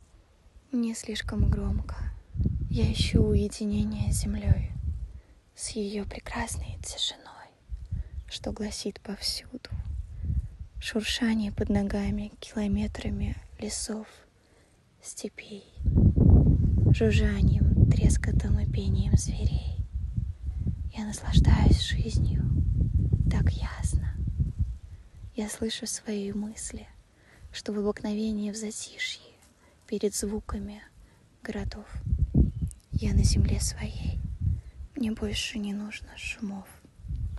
Жен, Другая/Молодой
Мои демо были записаны на самые разные устройства, чтобы вы могли ознакомиться со звучанием моего голоса.